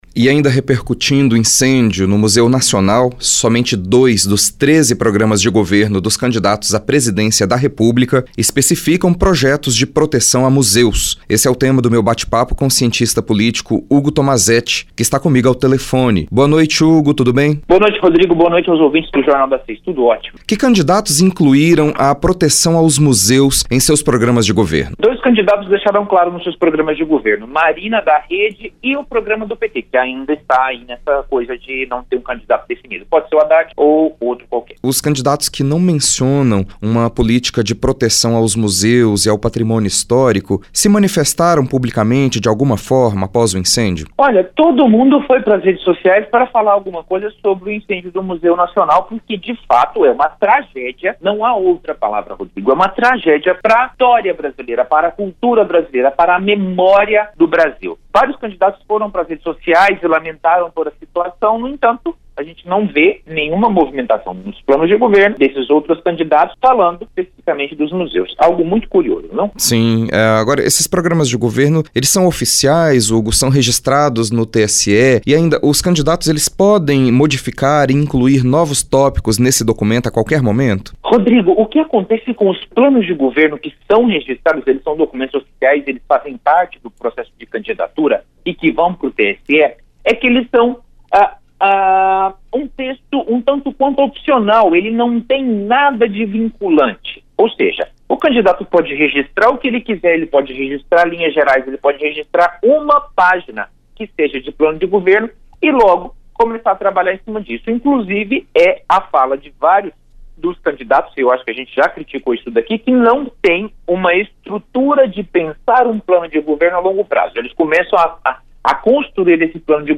A Rádio Universitária fez um apanhado da importância histórica, arqueológica, acadêmico científica e política do Museu Nacional, por meio de entrevistas realizadas com especialistas e veiculadas nos programas Intercampus (11h30) e Jornal das Seis (18h).
Áudio da Entrevista com cientista político